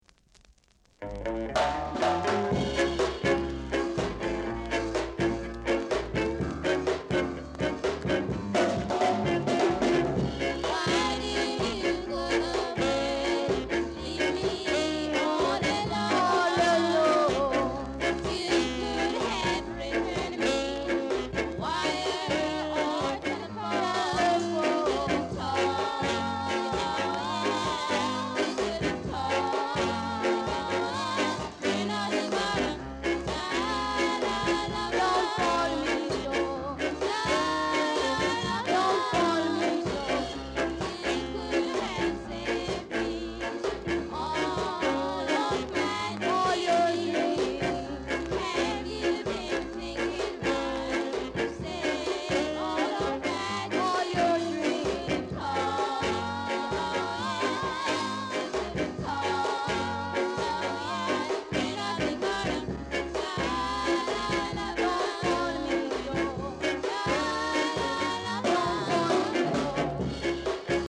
Ska Vocal Group
Hot ska vocal & nice inst!